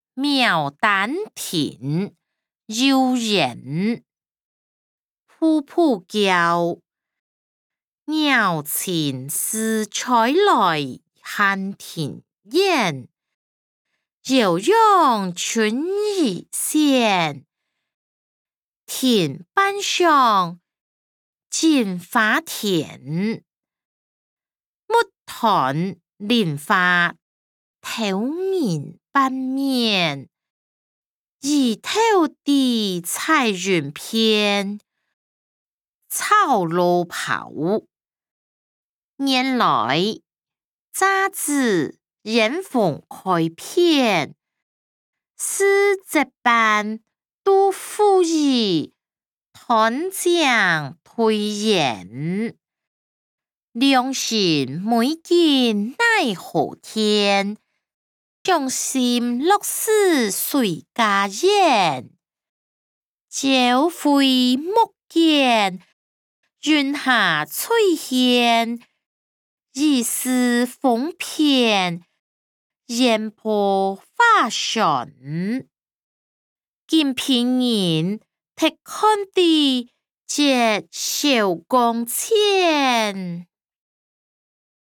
詞、曲-牡丹亭．遊園音檔(大埔腔)